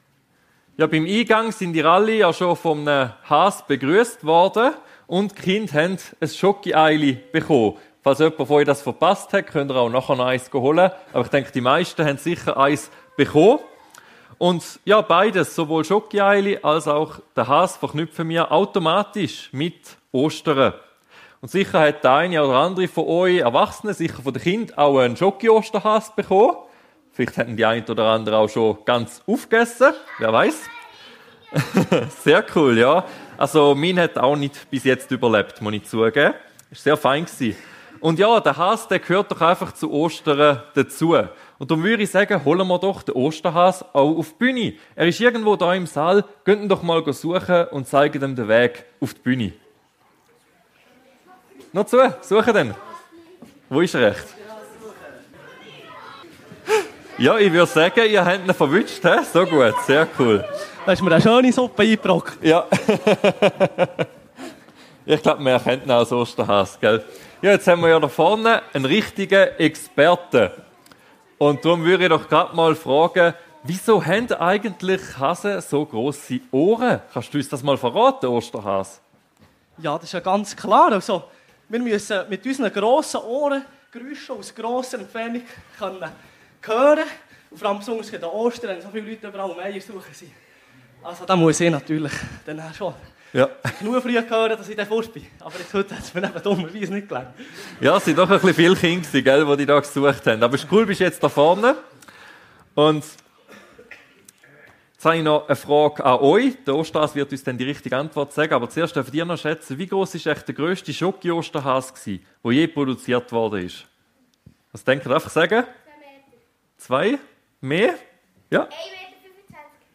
Jesus ist der Weg die Wahrheit und das Leben ~ FEG Sumiswald - Predigten Podcast